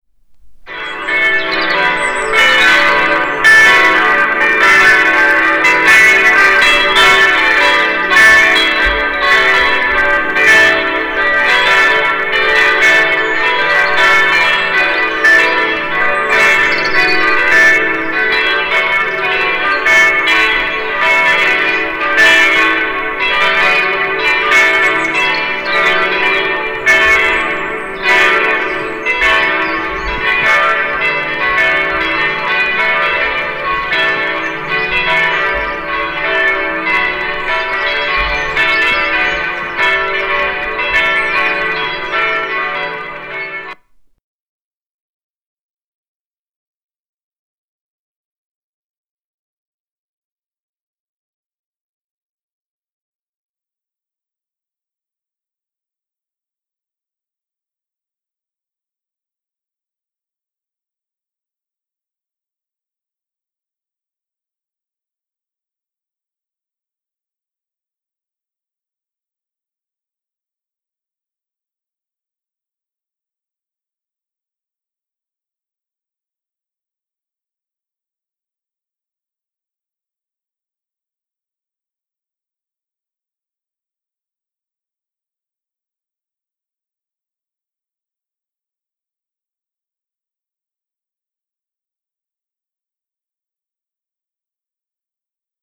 12-Glockengeläute.m4a